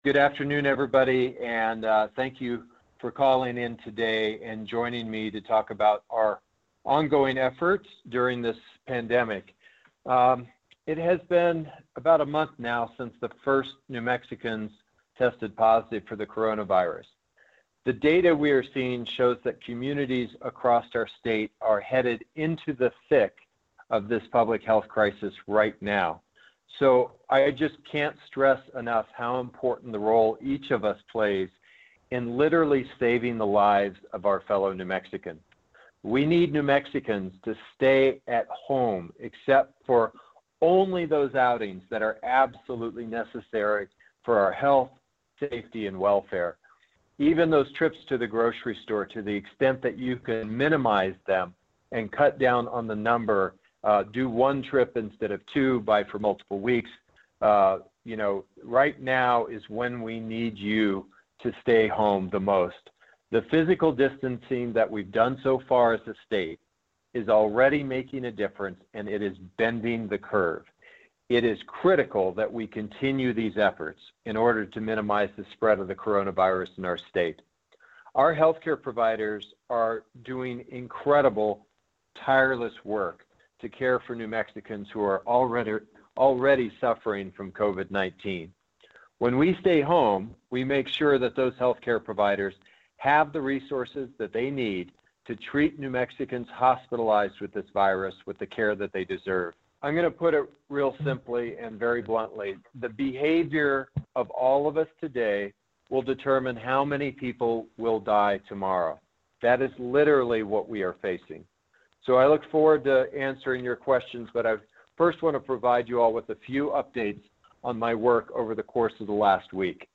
WASHINGTON – U.S. Senator Martin Heinrich (D-N.M.) today held a teleconference with New Mexico-based reporters to answer questions and discuss the latest on efforts to assist New Mexicans impacted by the COVID-19 pandemic.